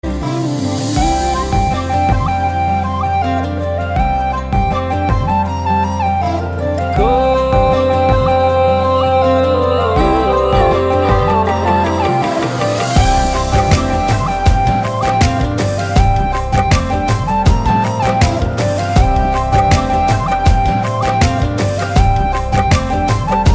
Flute Ringtones